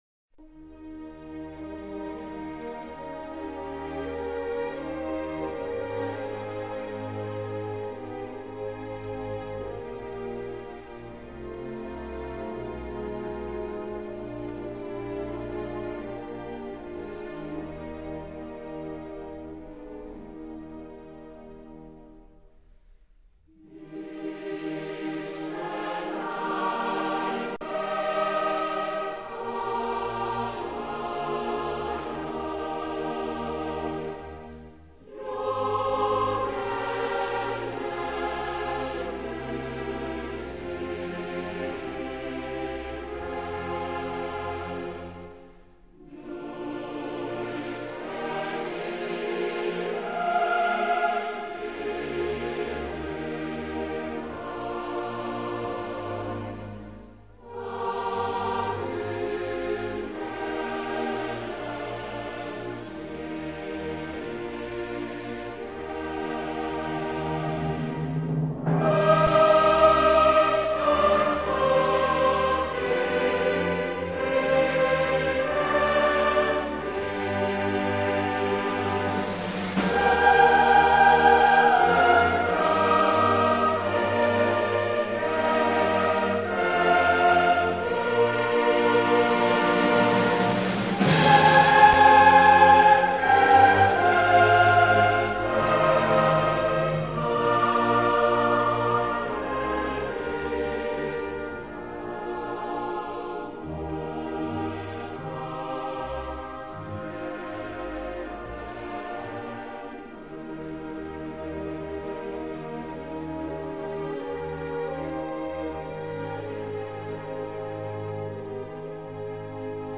himnusz.mp3